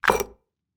CandleEquip.mp3